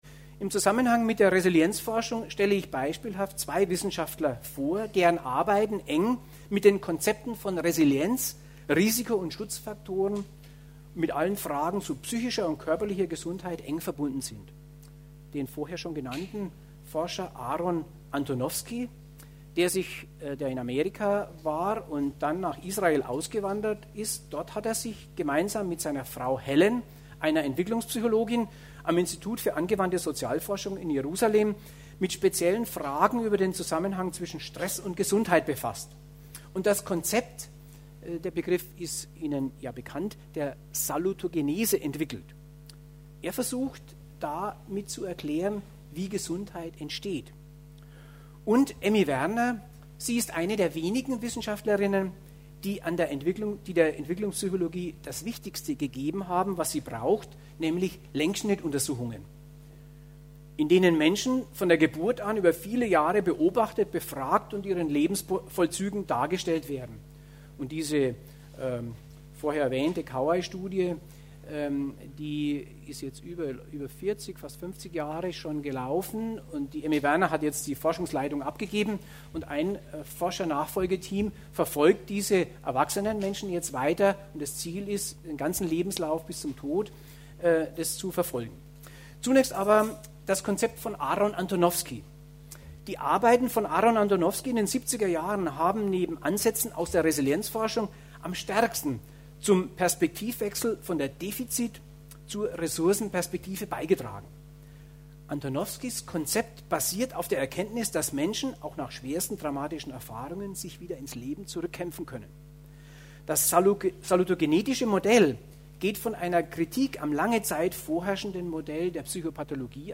MP3-Downloads des Salzburger Vortrags:
05_Resilienz-Vortrag_Salzburg.mp3